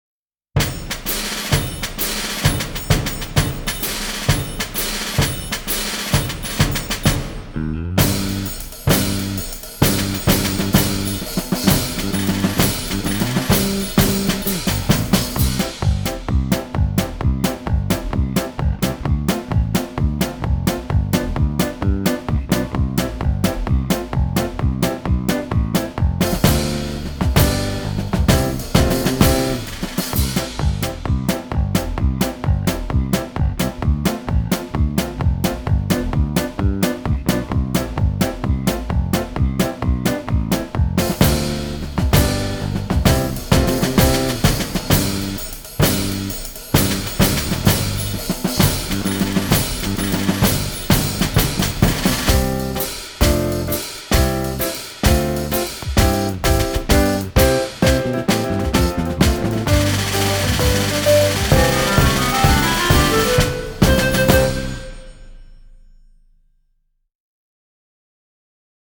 2曲とも、アップテンポで耳馴染みが良く、口ずさみたくなる楽しい曲となっています。
カラオケ